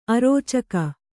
♪ arōcaka